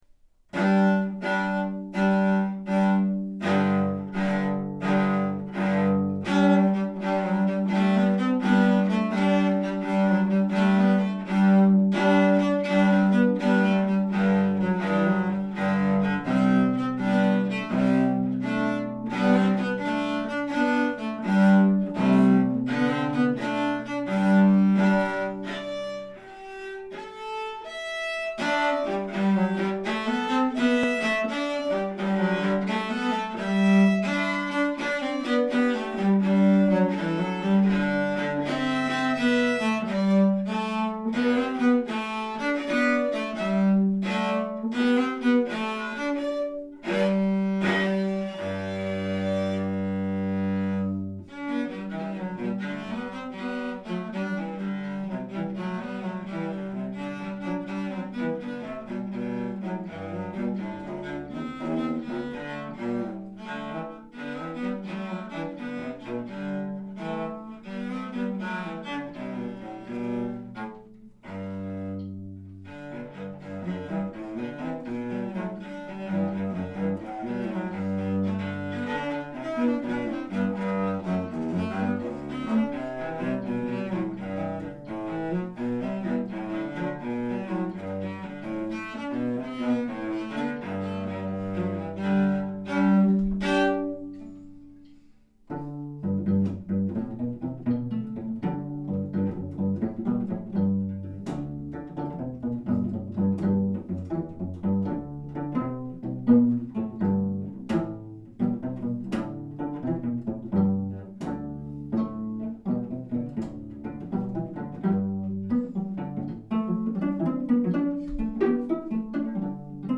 Cello Duet